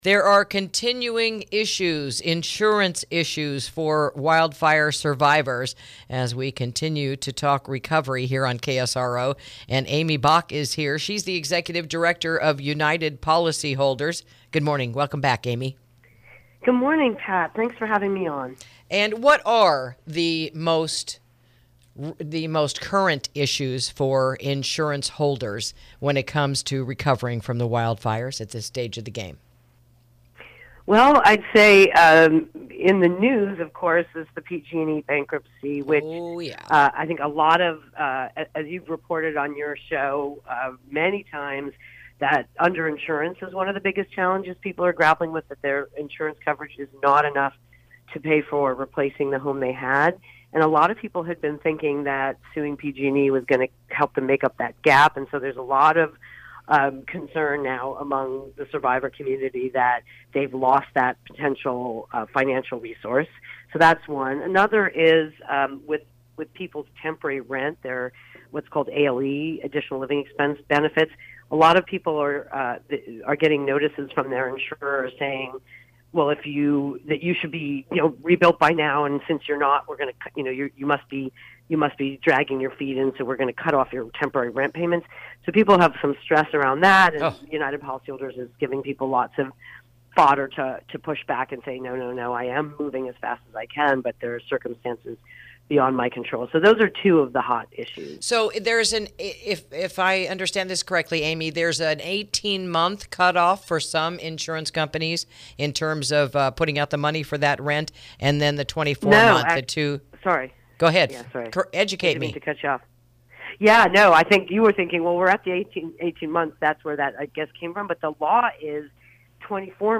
INTERVIEW: United Policyholders Address Fire Survivor Insurance Issues